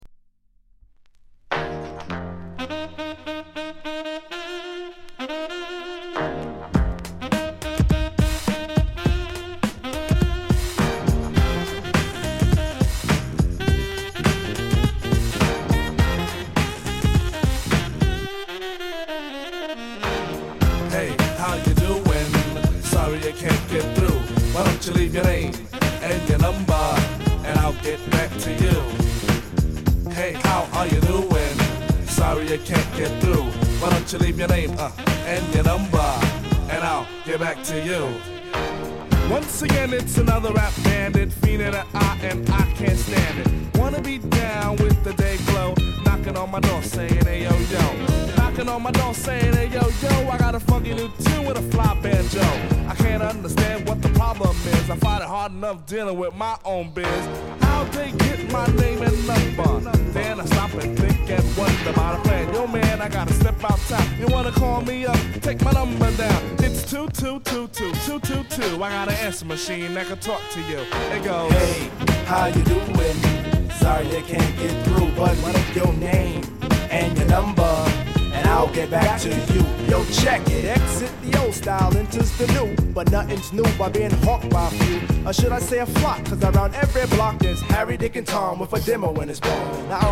category Rap & Hip-Hop